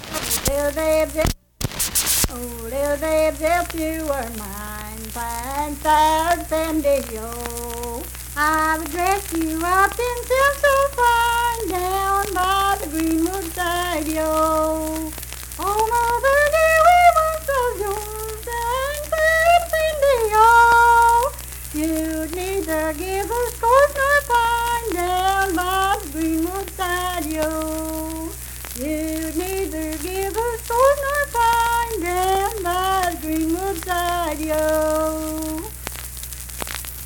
Unaccompanied vocal music performance
Verse-refrain, 2(4w/R).
Voice (sung)
Kirk (W. Va.), Mingo County (W. Va.)